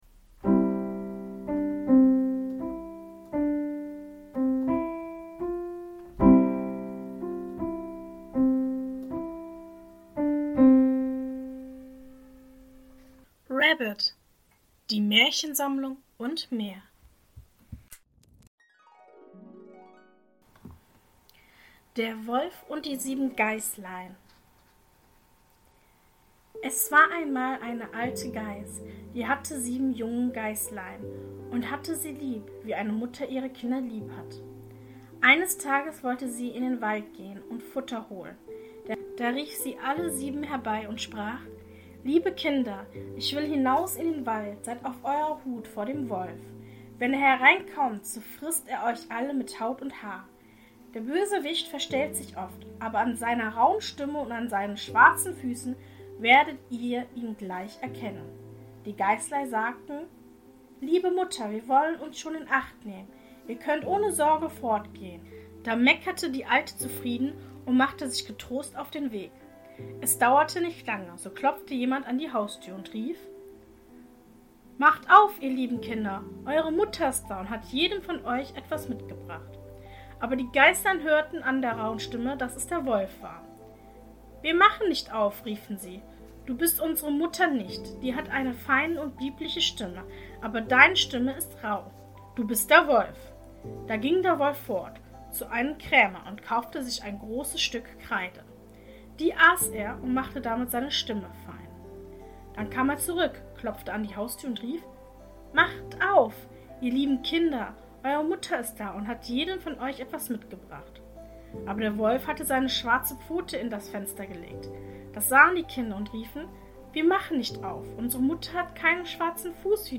In der heutigen Folge lese ich Folgendes vor: 1. Der Wolf und die sieben Geißlein 2. Brüderchen und Schwesterchen 3. Die Bremer Stadtmusikanten Mehr